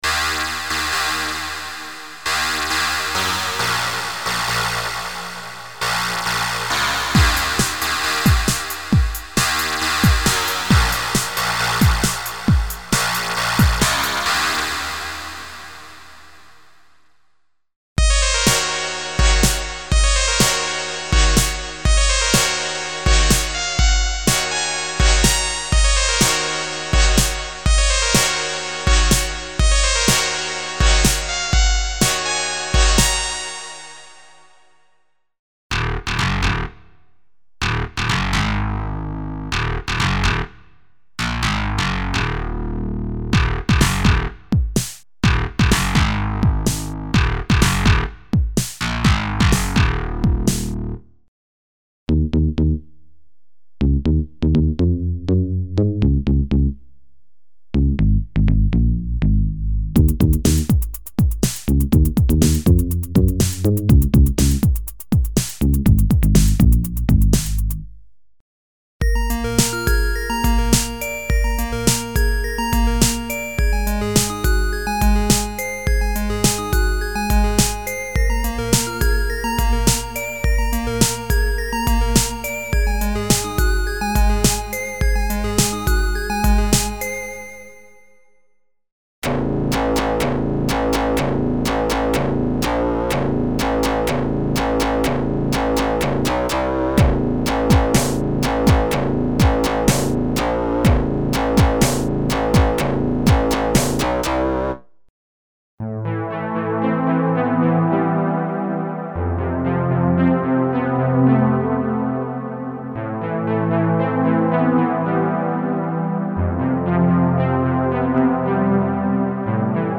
Emulations of vintage digital synthesizers - PPG, DX (FM synthesis) program variations (synth basses, mono leads, poly synths and seq. programs).
Info: All original K:Works sound programs use internal Kurzweil K2500 ROM samples exclusively, there are no external samples used.